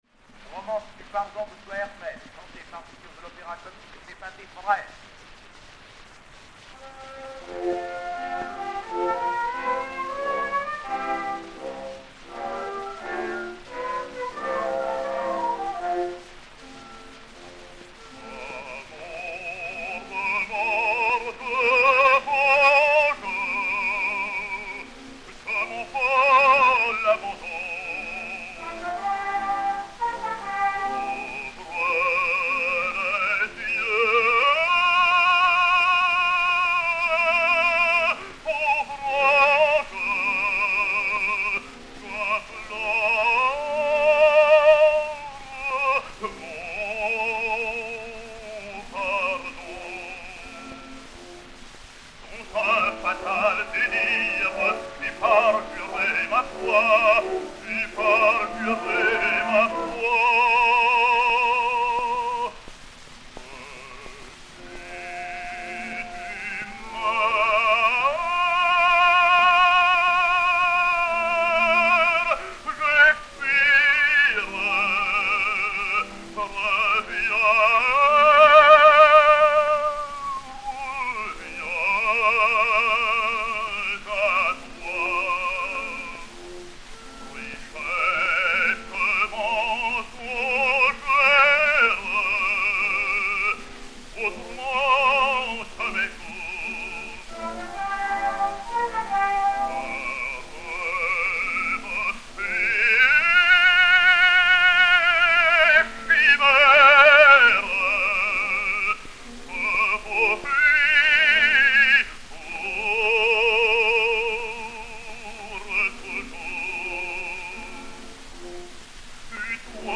Daniel Vigneau (Hoël) et Orchestre